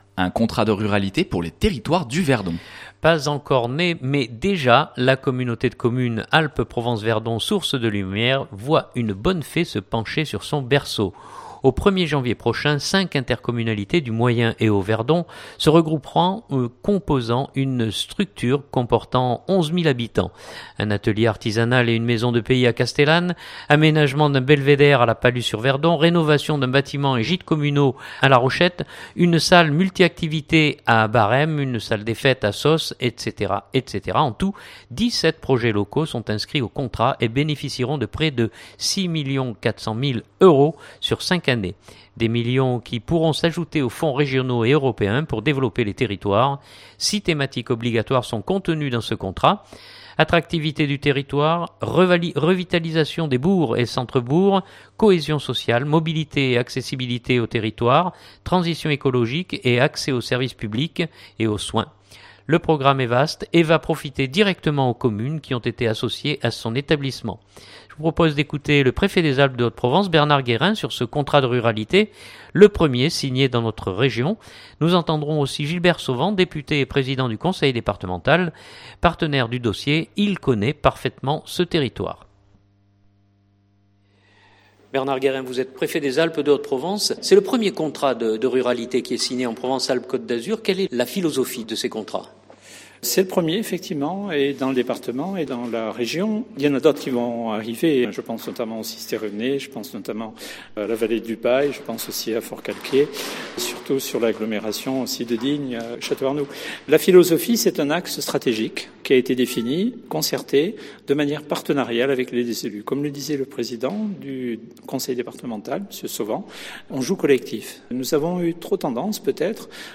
Je vous propose d’écouter le Préfet des Alpes de Haute-Provence Bernard Guérin sur ce contrat de ruralité, le premier signé dans notre région.